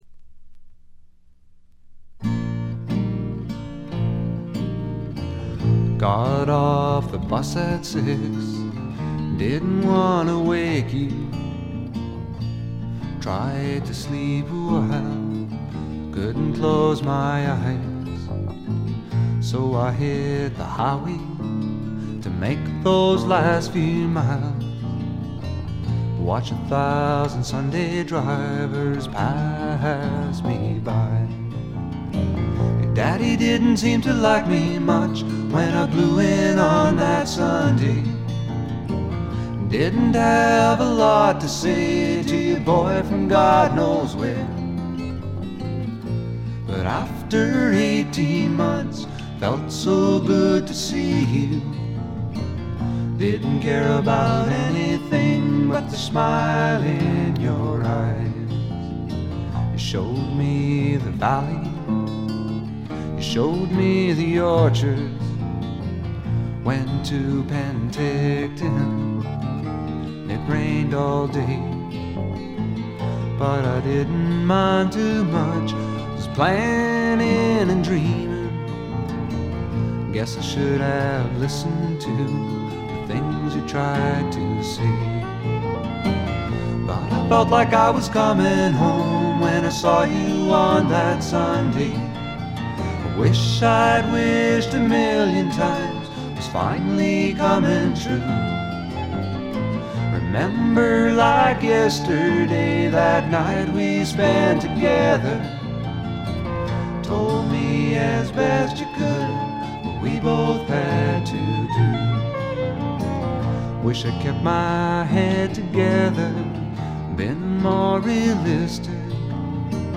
軽微なチリプチほんの少し。
試聴曲は現品からの取り込み音源です。